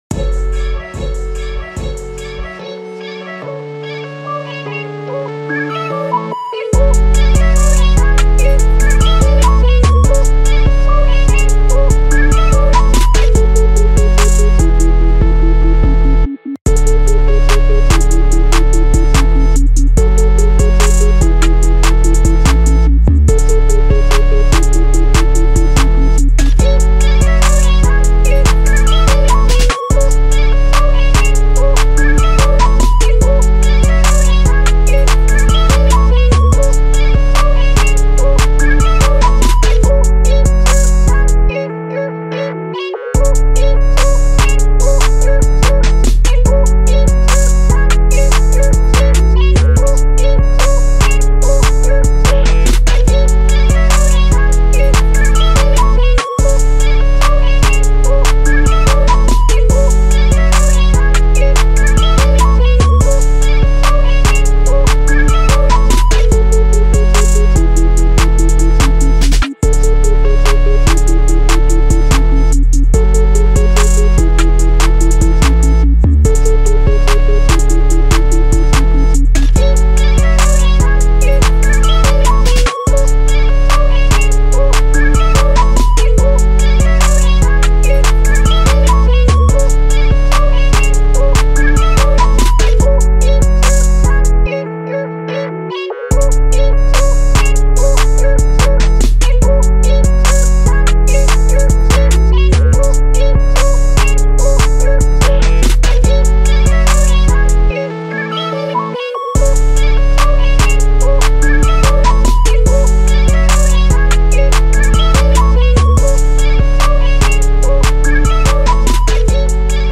To celebrate our colonial overlords, Club Penguin Journey, today’s audio comes from a CPJ Party… but which one?